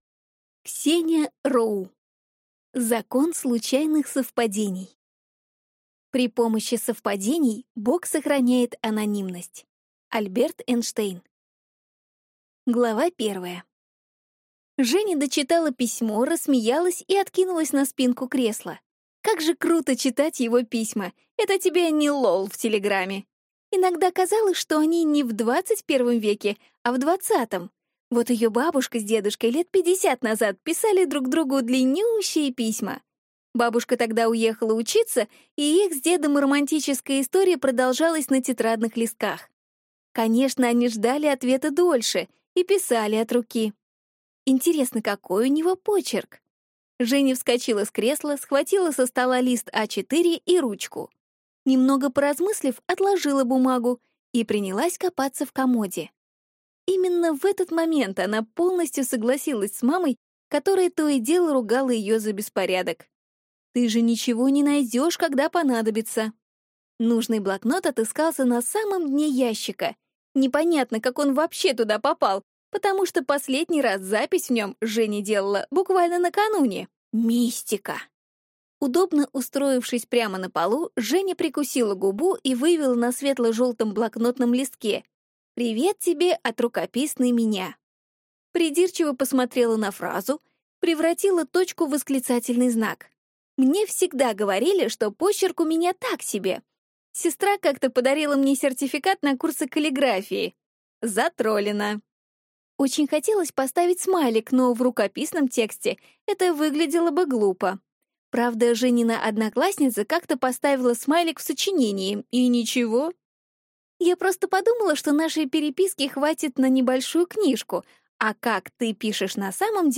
Аудиокнига Закон случайных совпадений | Библиотека аудиокниг